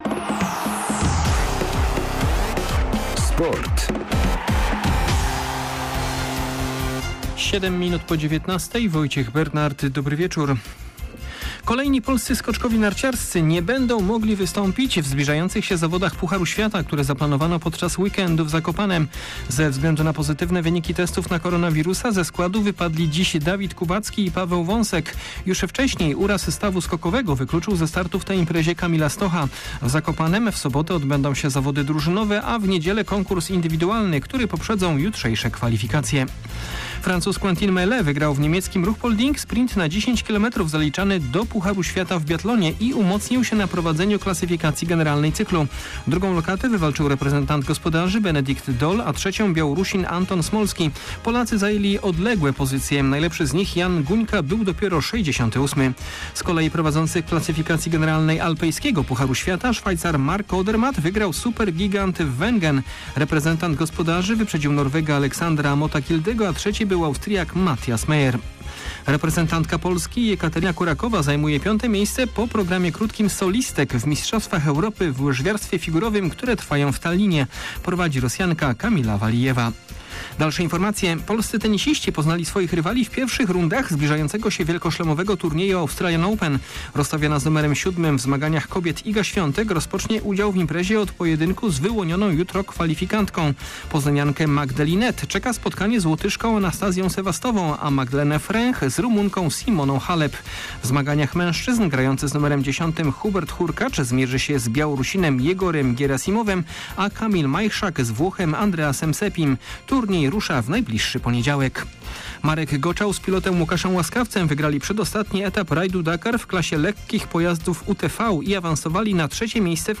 13.01.2022 SERWIS SPORTOWY GODZ. 19:05